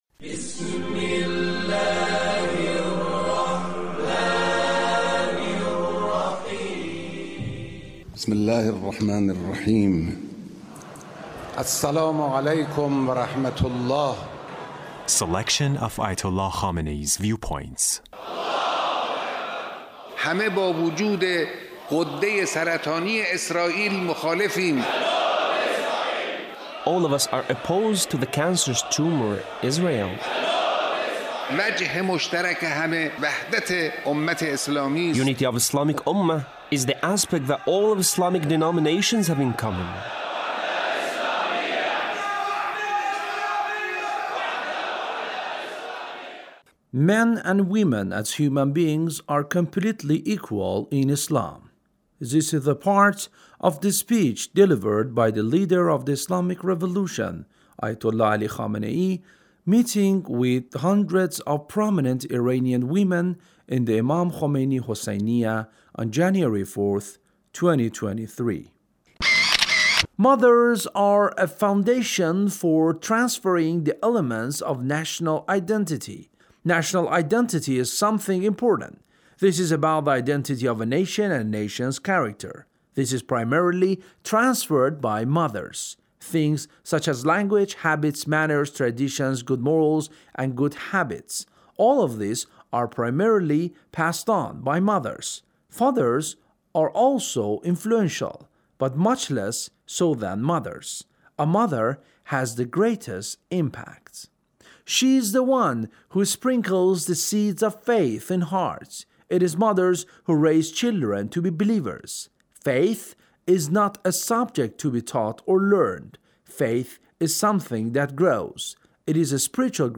Leader's Speech meeting with hundreds of prominent Iranian women